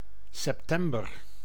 Ääntäminen
IPA: /ˈsyːsˌkuu/